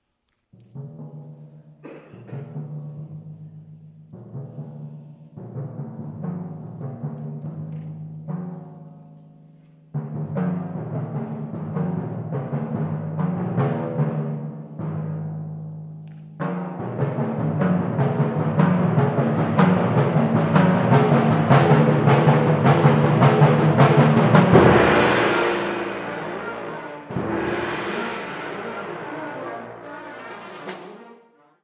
Unterkategorie Eröffnungsmusik
Besetzung Ha (Blasorchester)